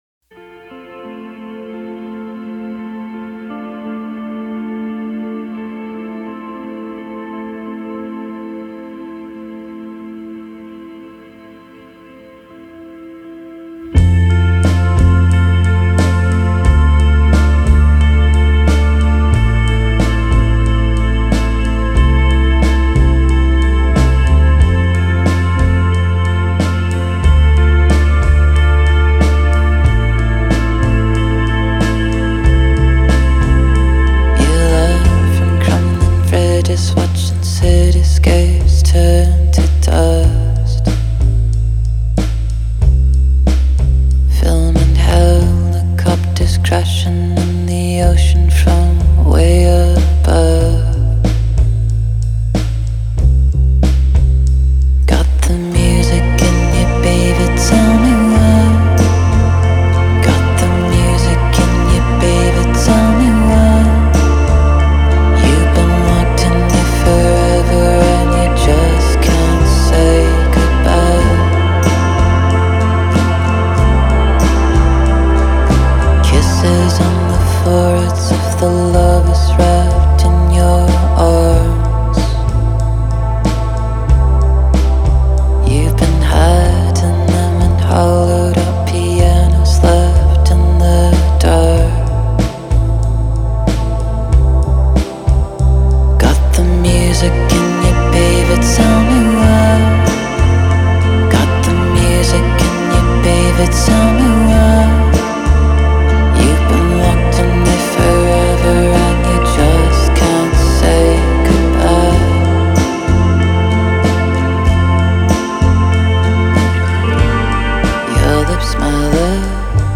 DOUCEUR MUSICALE